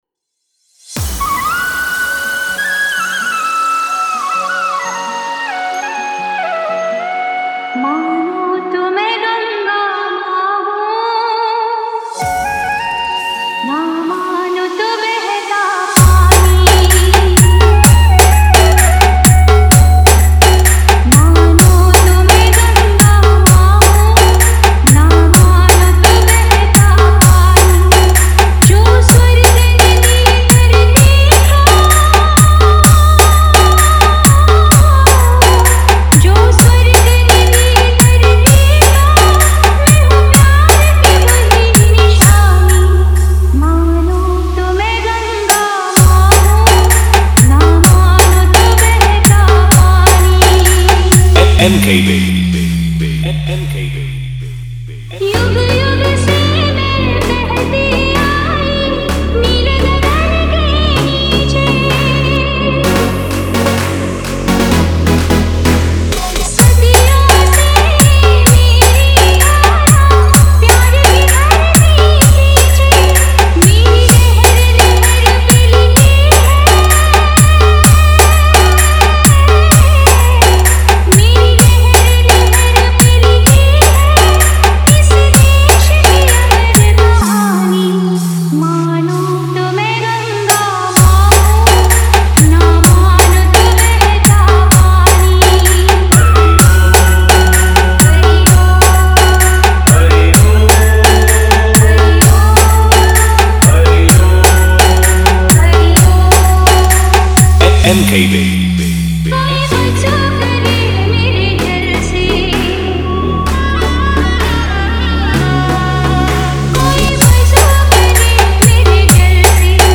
Bhakti DJ Vibration Mix
Devotional Bass Mix, Indian Bhakti EDM